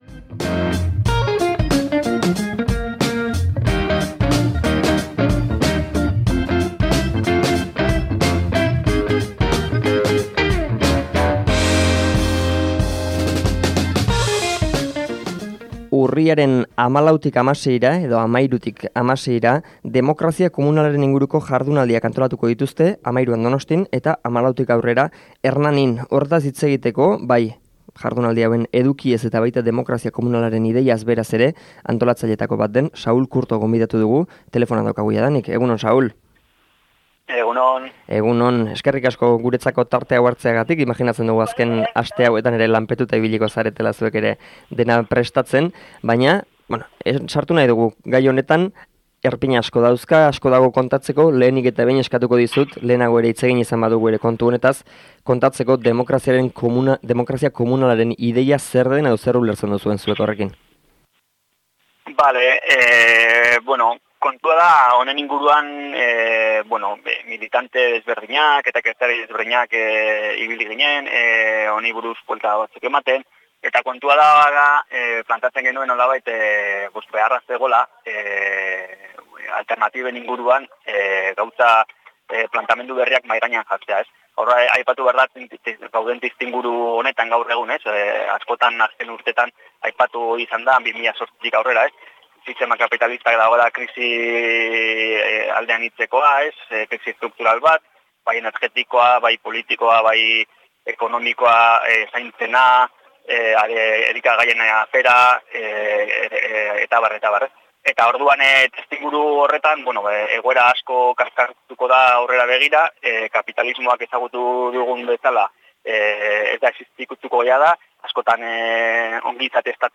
Elkarrizketa osoa hemen entzungai.